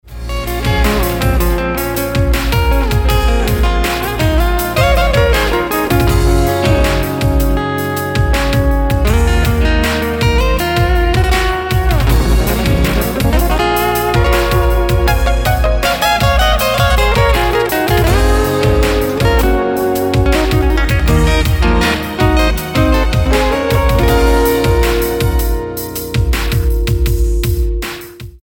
• Качество: 320, Stereo
гитара
спокойные
без слов
инструментальные
Progressive Metal
melodic metal
Гитара, инструментальная композиция